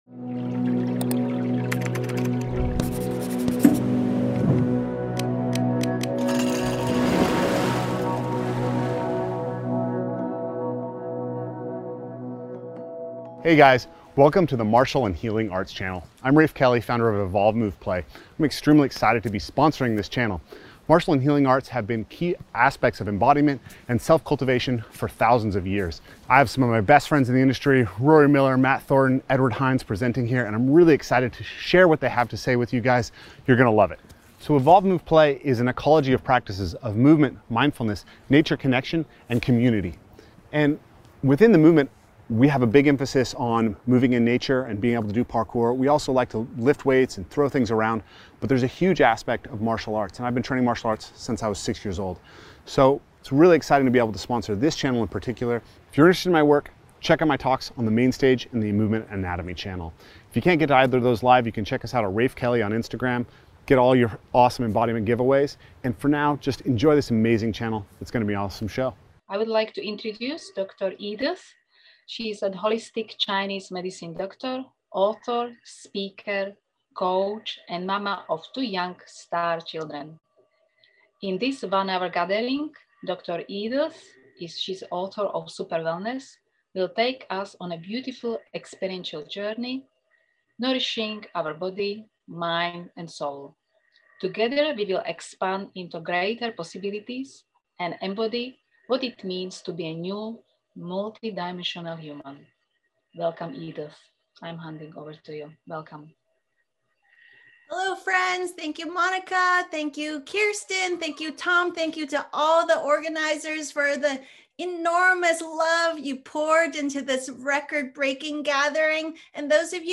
Likely soothing
Guided Practices